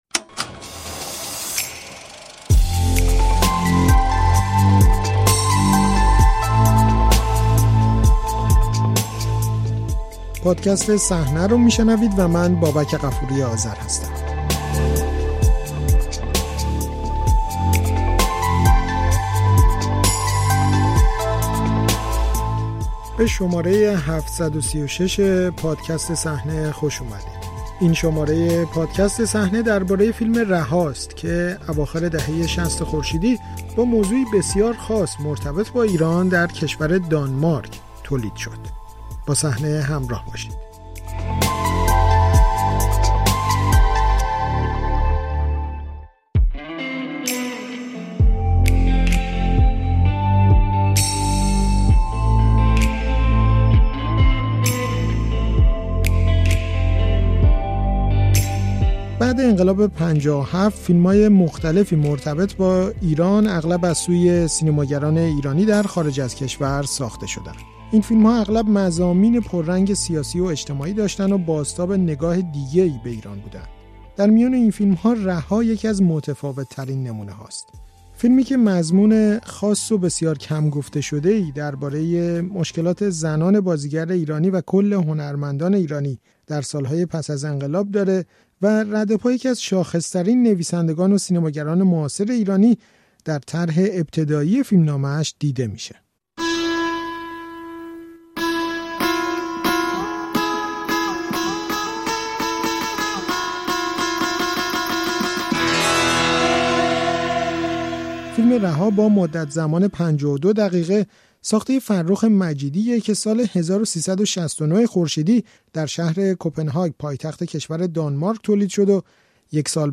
شماره ۷۳۶ پادکست «صحنۀ» رادیو فردا یک گزارش تحقیقی است درباره فیلم رها.